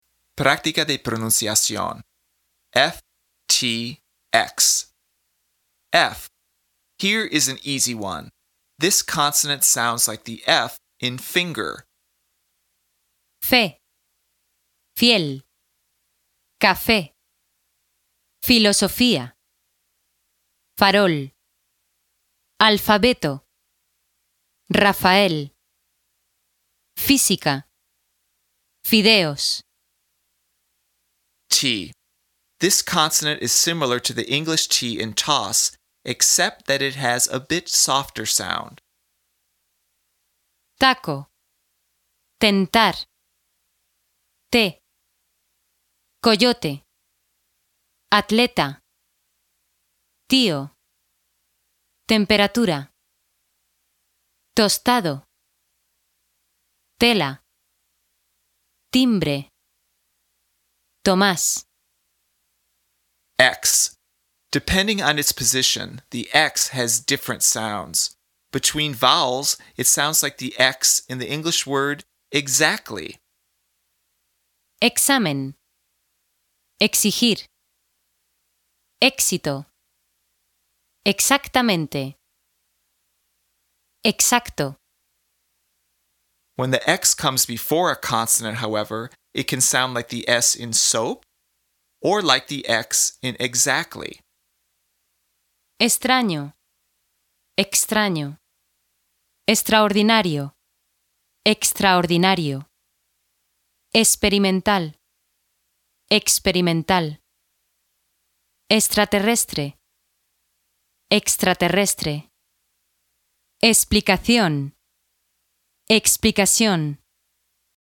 PRÁCTICA DE PRONUNCIACIÓN
This consonant sounds like the “f” in “finger.”
This consonant is similar to the English “t” in “toss,” except that it has a bit softer sound.
Between vowels, it sounds like the “x” in the English word “exactly.”
Note: The words “Texas” and “México” are pronounced “Tejas” and “Méjico.”